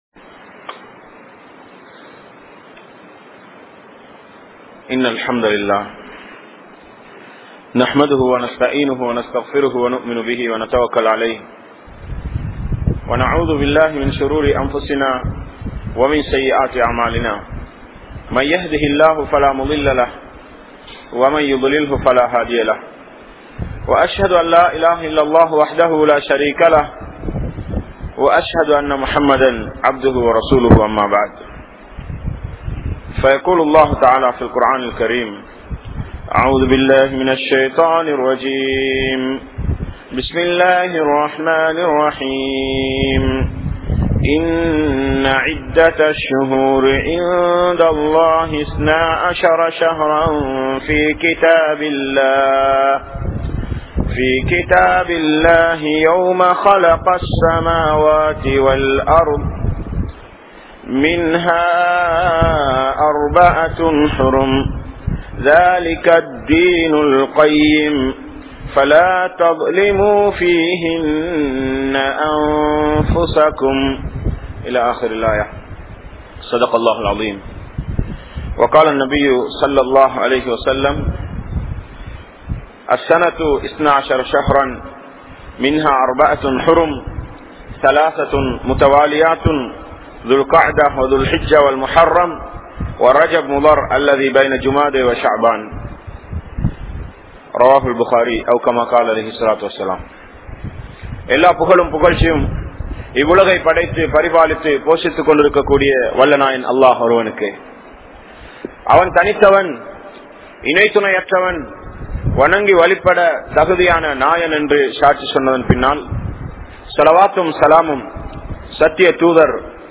Allah`vin Uthavi Veanduma? (அல்லாஹ்வின் உதவி வேண்டுமா?) | Audio Bayans | All Ceylon Muslim Youth Community | Addalaichenai
Kanampittya Masjithun Noor Jumua Masjith